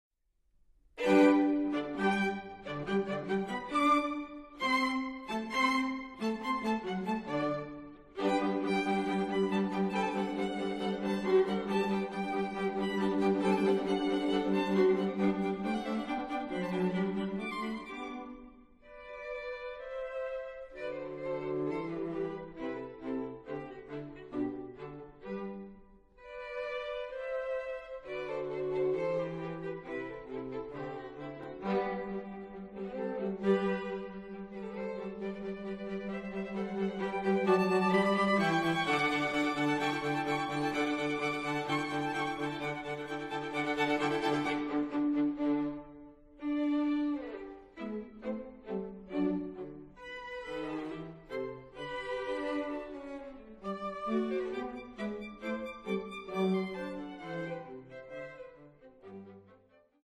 Репертуар дворца | Струнный квартет Нона